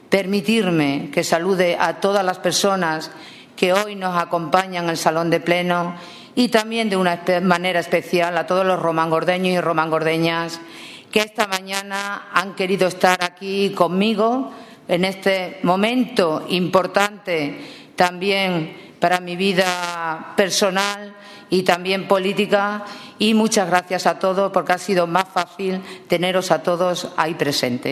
CORTES DE VOZ
Fue al terminar la sesión cuando Charo Cordero quiso dirigirse a sus conciudadanos, dándoles las gracias.